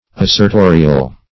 Search Result for " assertorial" : The Collaborative International Dictionary of English v.0.48: Assertorial \As`ser*to"ri*al\, a. Asserting that a thing is; -- opposed to problematical and apodeictical .